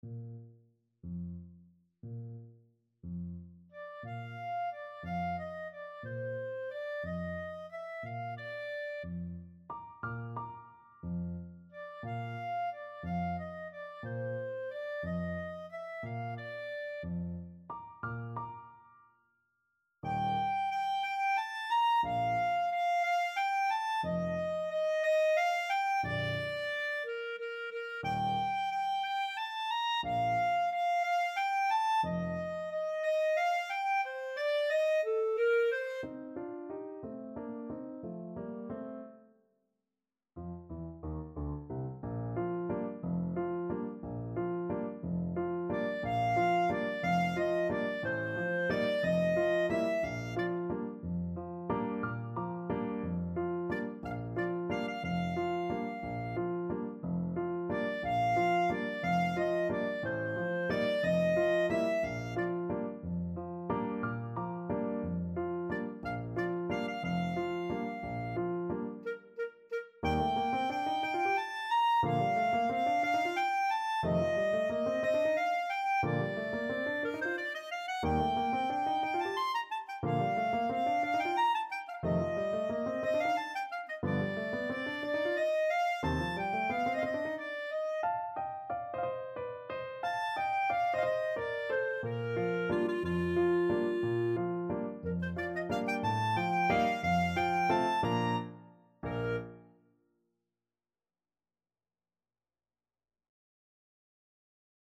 Clarinet
Bb major (Sounding Pitch) C major (Clarinet in Bb) (View more Bb major Music for Clarinet )
6/8 (View more 6/8 Music)
Pochissimo pi mosso = 144 . =60
Classical (View more Classical Clarinet Music)